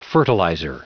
Prononciation du mot fertilizer en anglais (fichier audio)
Prononciation du mot : fertilizer